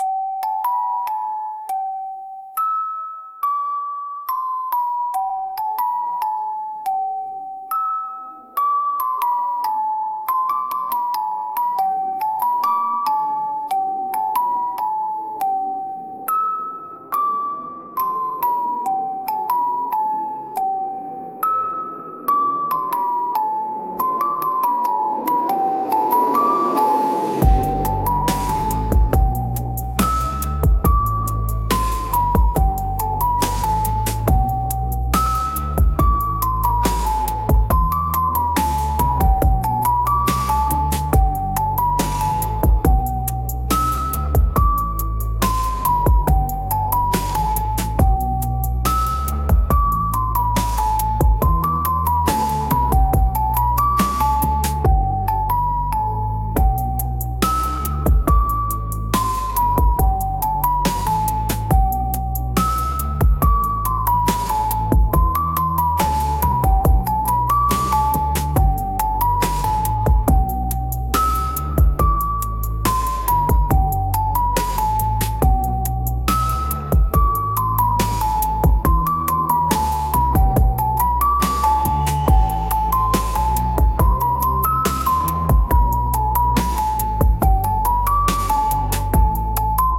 癒し、リラックス